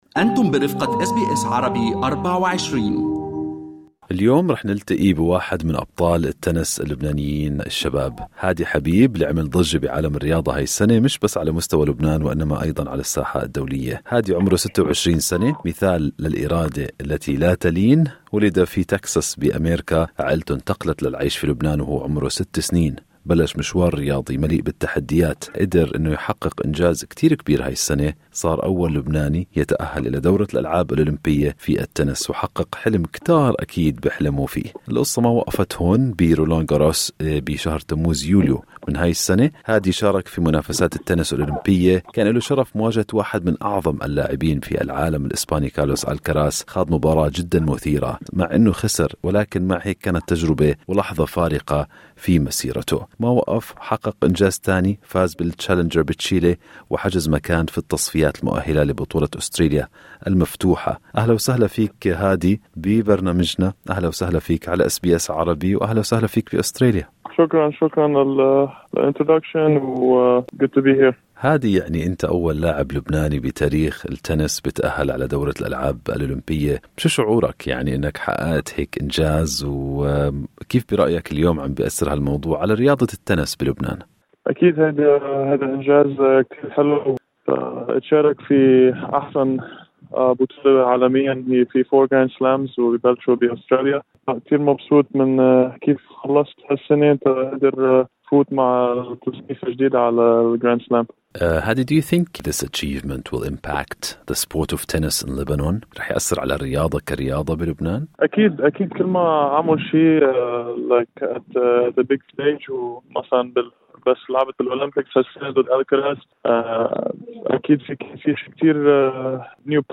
حاورناه وعدنا بالحديث التالي.